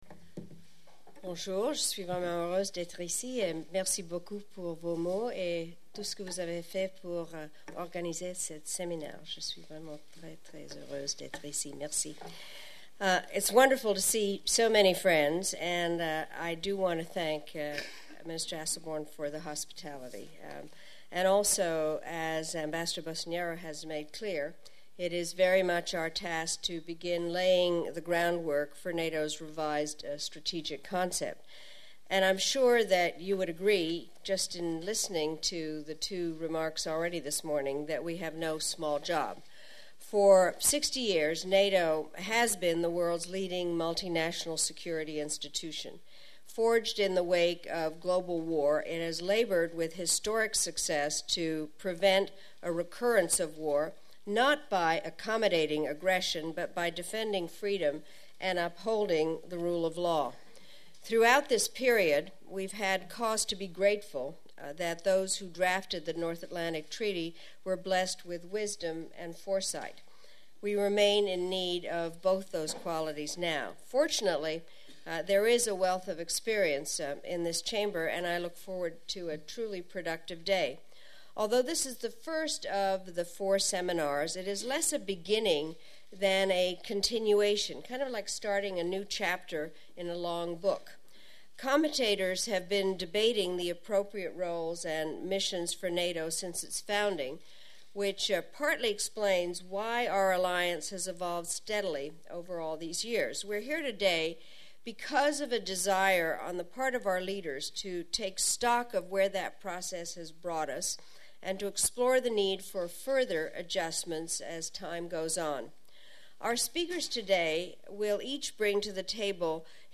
NATO’s new Strategic Concept discussed at Luxembourg seminar
09:40 Chair’s remarks
The Hon. Madeleine K. Albright, Chair of the Group of Experts       ENG .